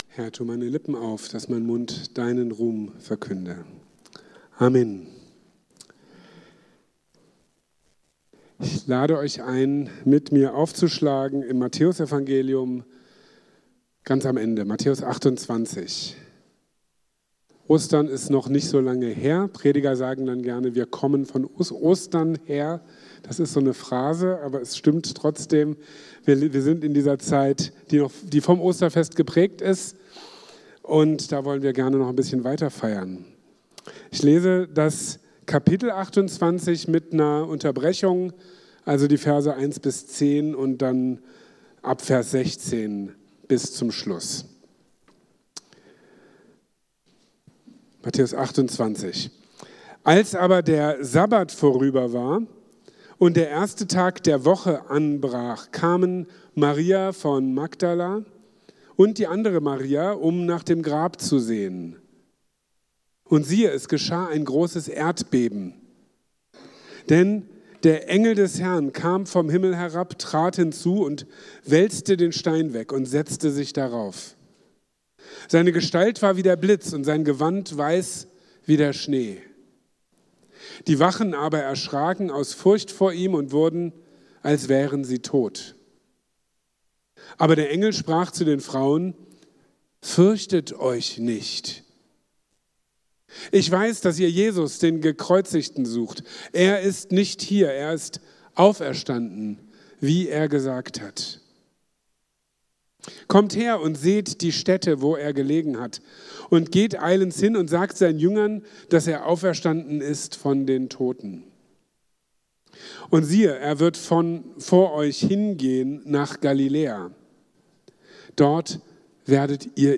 Den Auferstandenen anbeten | Marburger Predigten